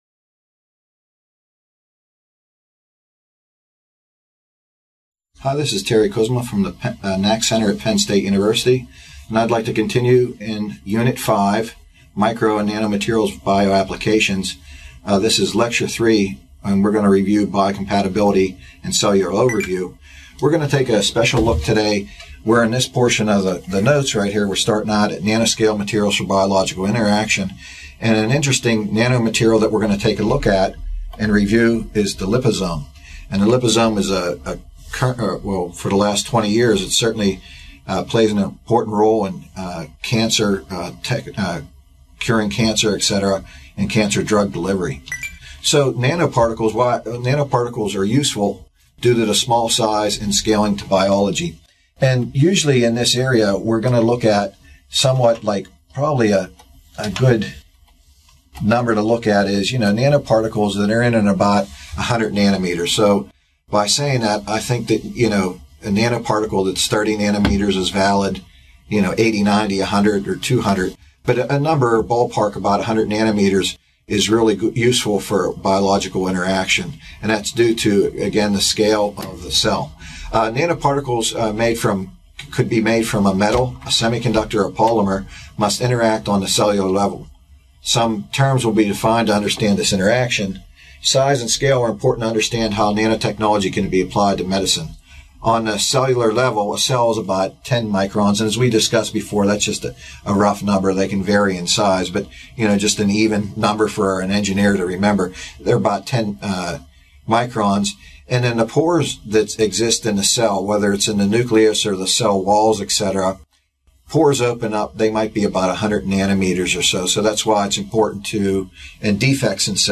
This video, provided by the Nanotechnology Applications and Career Knowledge Support (NACK) Center at Pennsylvania State University, is part three of a four-part lecture on the interactions between biological entities and products crafted on a nanoscale, with a focus on nanoparticles for drug delivery.